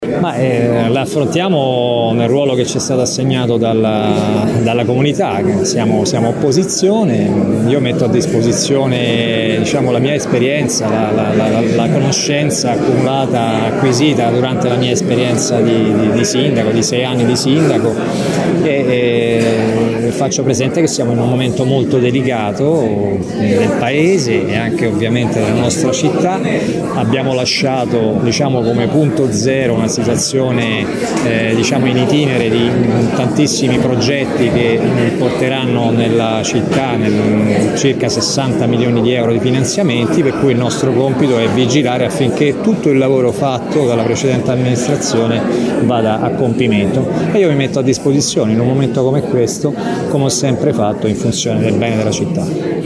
“Siamo dove ci hanno voluto i cittadini”, ha detto il candidato sconfitto parlando a margine del Consiglio.